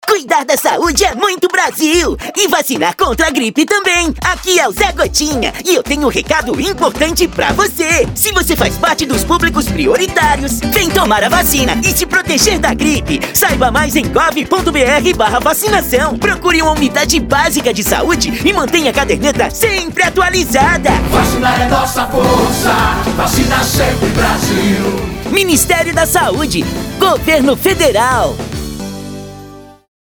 Áudio - Spot 30s - Vacinação contra a gripe .mp3 — Ministério da Saúde